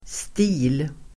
Uttal: [sti:l]